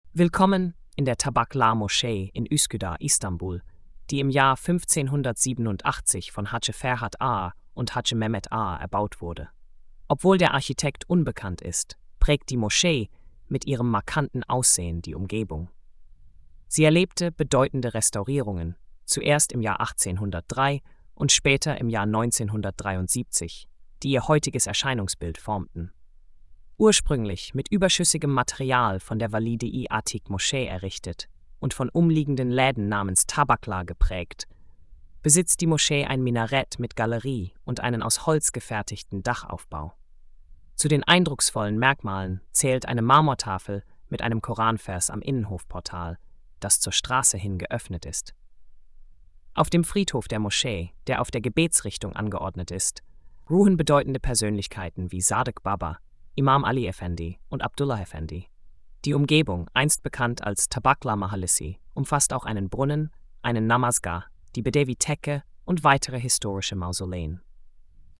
Audio Erzählung